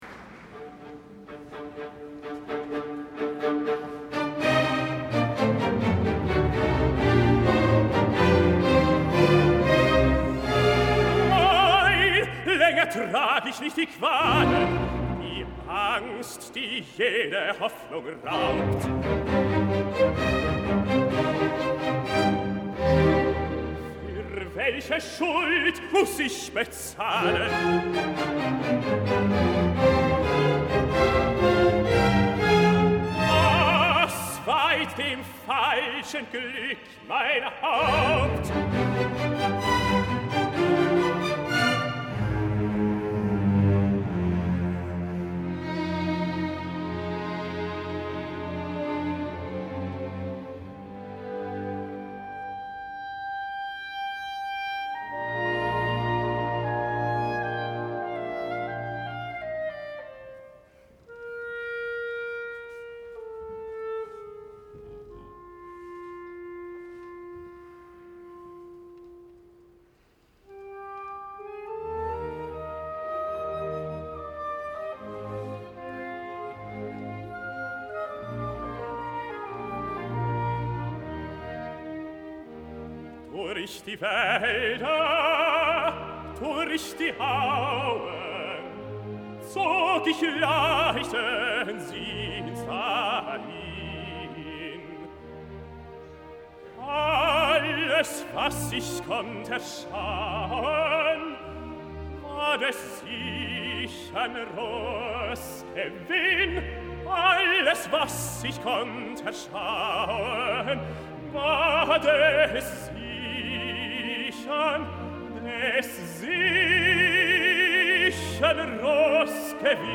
Neujahrskonzert 2018, Deutsche Radiophilharmonie
Deutsche Radiophilharmonie
Dirigent: Pietari Inkinen
LIVE